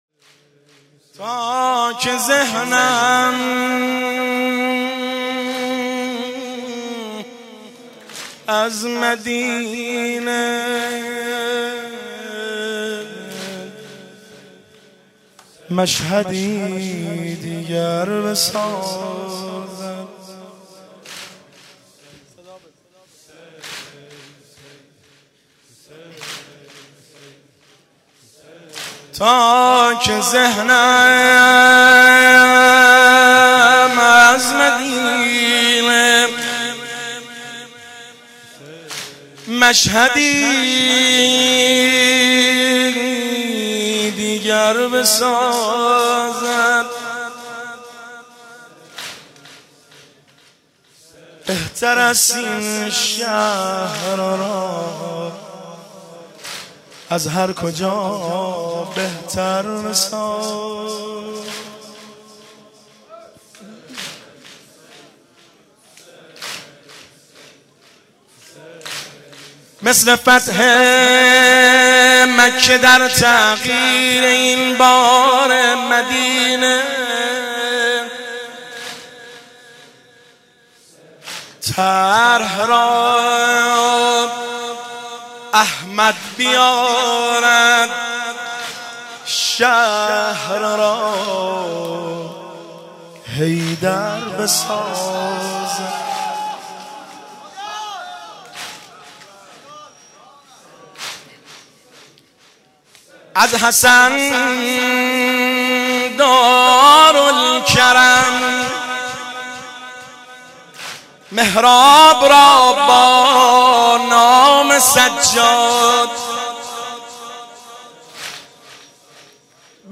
شعر